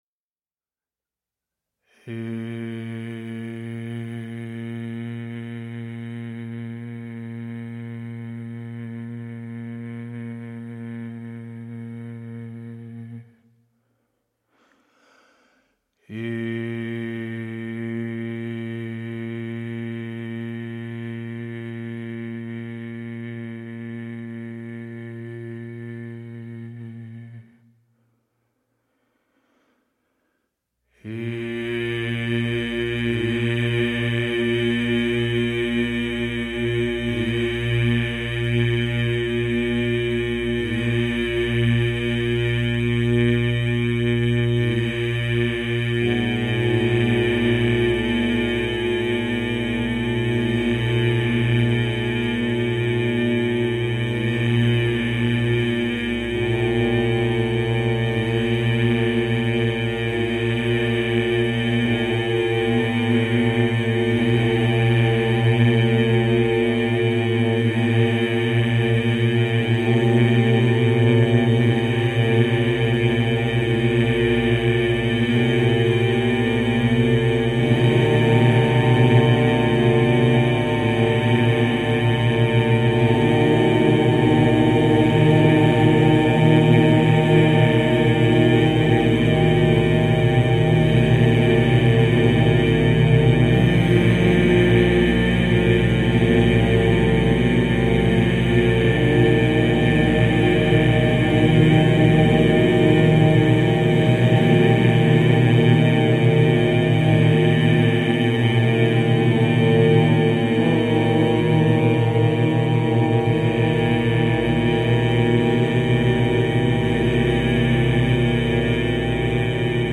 electro-acoustic solo works
for baritone